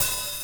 Closed Hats
Boom-Bap Hat OP 83.wav